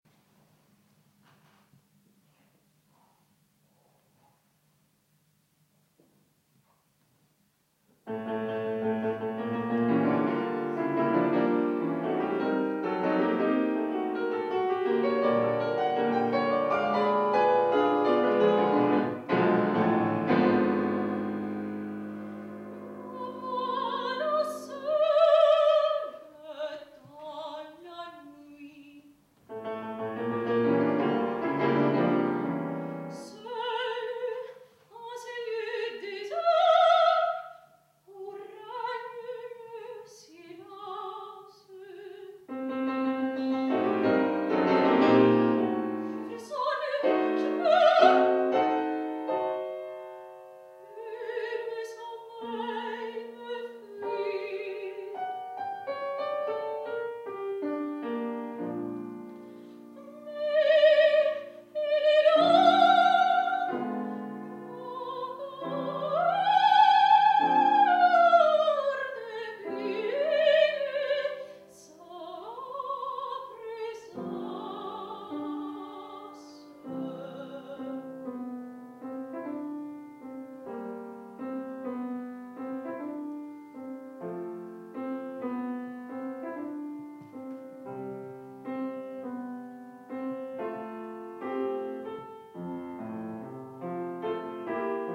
Concerto "Sulle ali del canto"